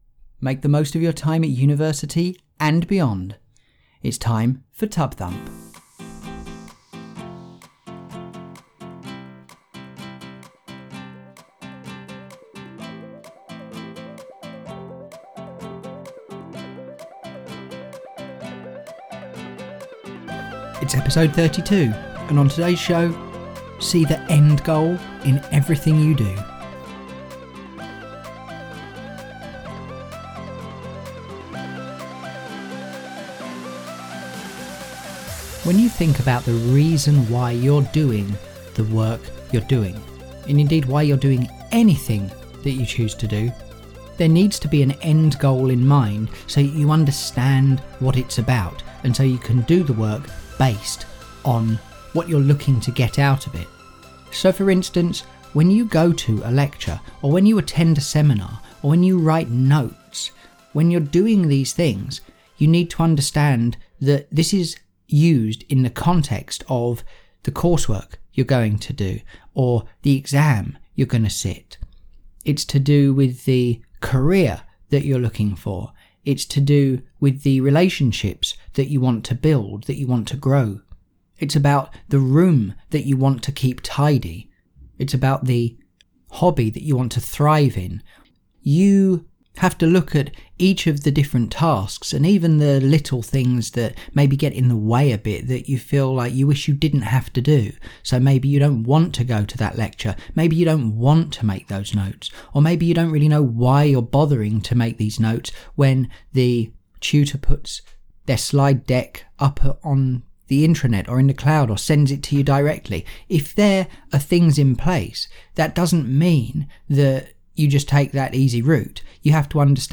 Music for TUB-Thump is Life, by Tobu, which is released under a Creative Commons license.